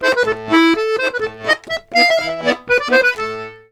C120POLKA2-L.wav